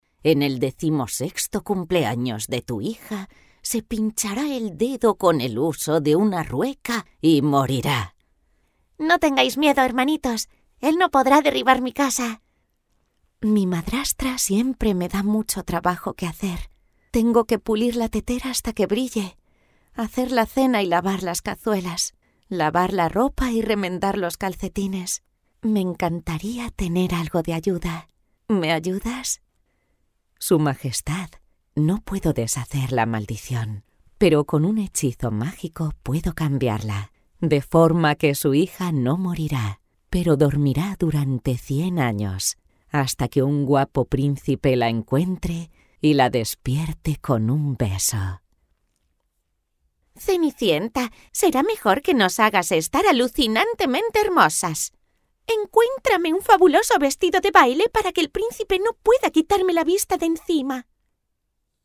PERFIL VOZ: Rotunda, cálida, clara, carismática, enérgica, vibrante, entusiasta, sofisticada, sensual, versátil, convincente, épica, profunda, elegante.
I have a castillian, native Spanish accent.
demo_personajes_characters.mp3